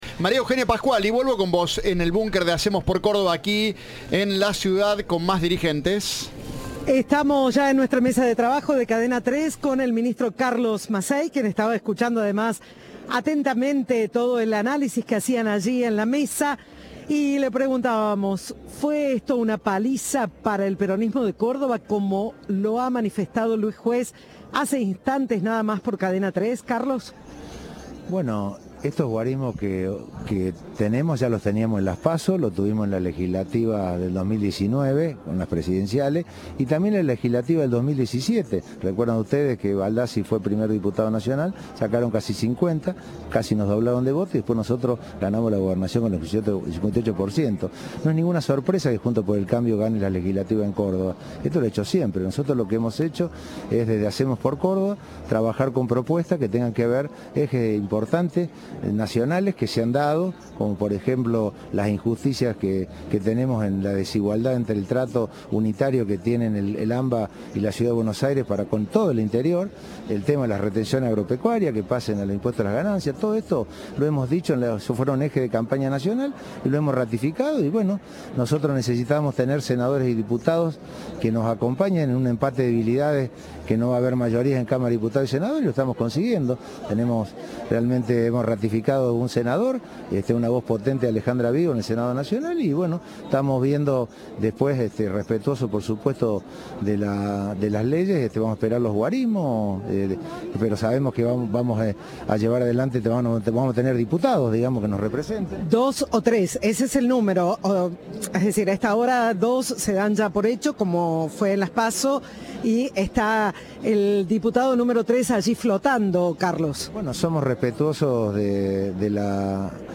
Entrevista de "Operativo Elecciones 2021".